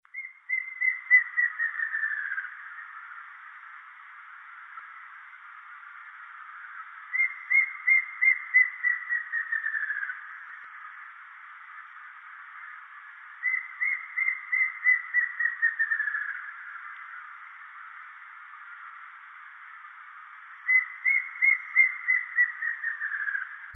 Choca Amarilla (Dysithamnus mentalis)
Nombre en inglés: Plain Antvireo
Fase de la vida: Adulto
Localidad o área protegida: Reserva Privada y Ecolodge Surucuá
Condición: Silvestre
Certeza: Vocalización Grabada
CHOCA-AMARILLA.MP3